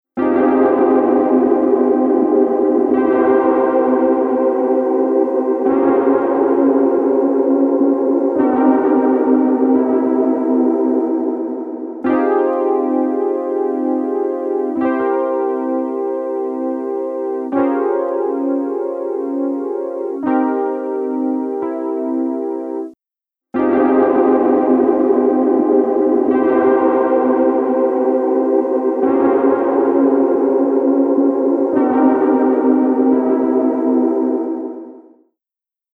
MicroPitchは、サウンドを自然に太くする高解像度ピッチシフトと、ドラマチックなスラップバックを生み出すディレイを融合したプラグインです。
MicroPitch | Poly Synth | Preset: Bottomless Drop
MicroPitch-Eventide-Poly-Synth-Bottomless-Drop.mp3